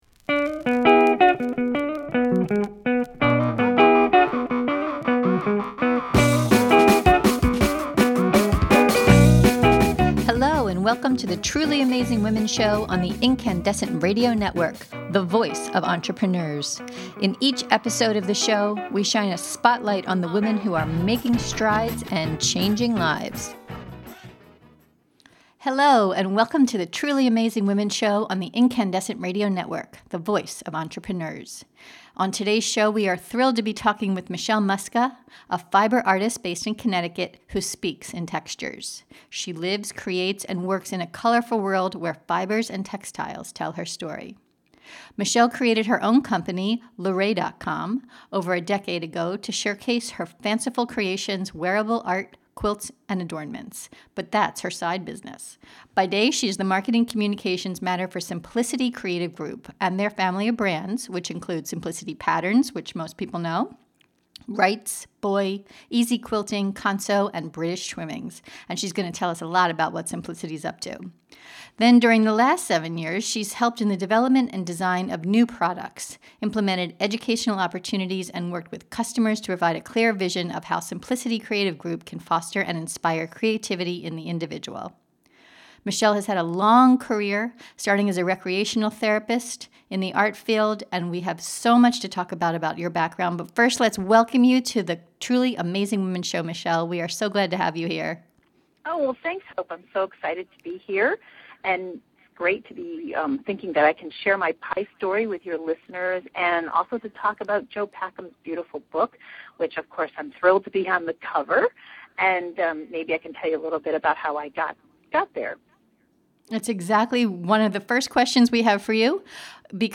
In this podcast interview you’ll learn: About her delicious pie: its history — and given the biographical theme of “Pie-orgraphy” — what the Midsummer Night’s Dream Pie tell us about her.